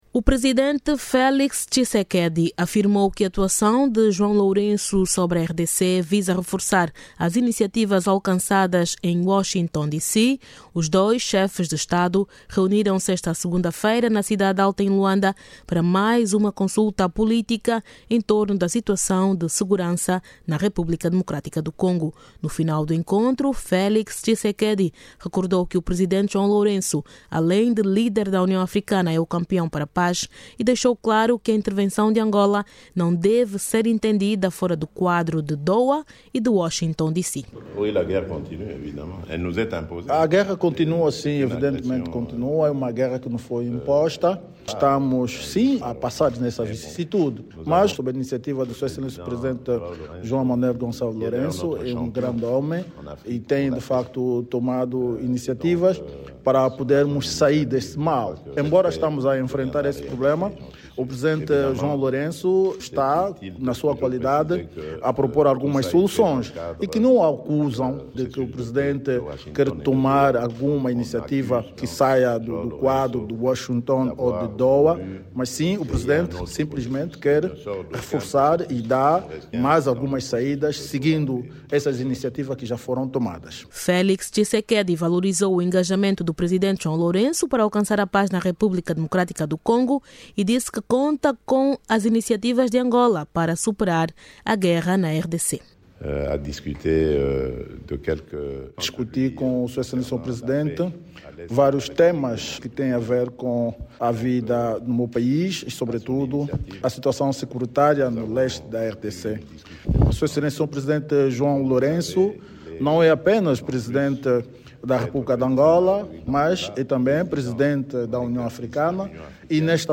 Jornalista